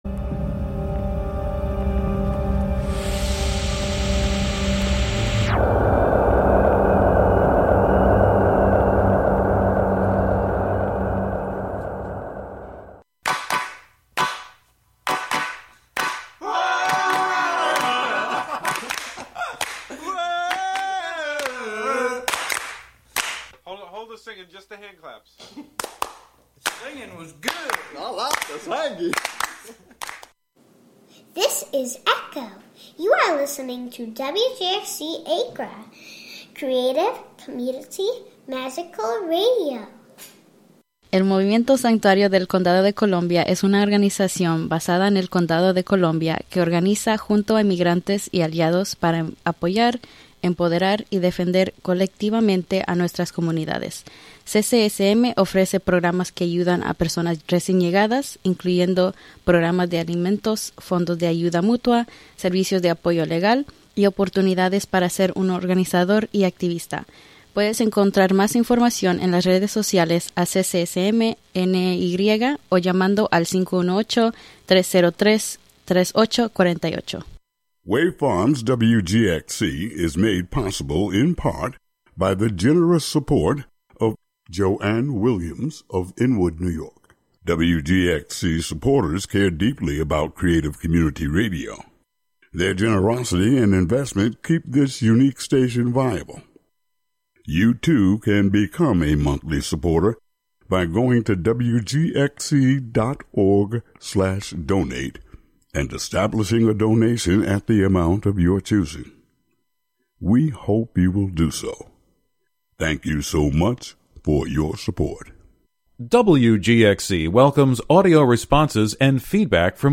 Live from the Catskill Maker Syndicate space on W. Bridge St. in Catskill, "Thingularity" is a monthly show about science, technology, fixing, making, hacking, and breaking with the amorphous collection of brains comprising the "Skill Syndicate."